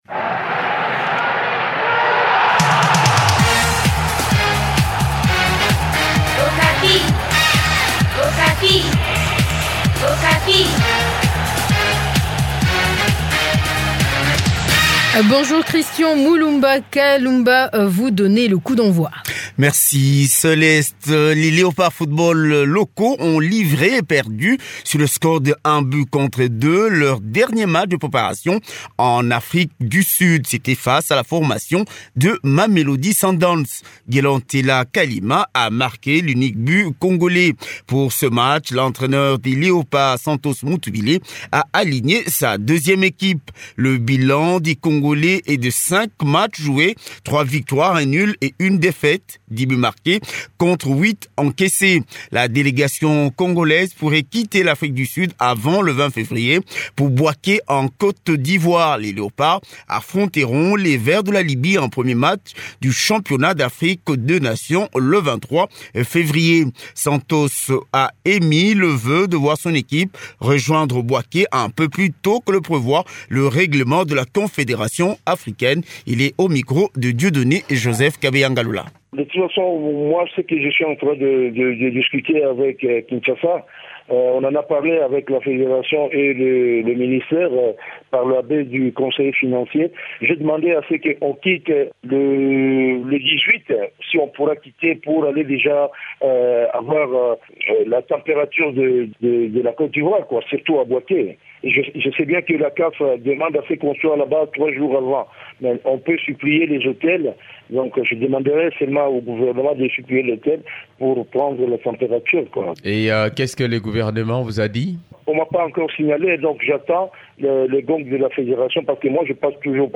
Journal des Sports